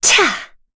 Yuria-Vox_Attack3_kr.wav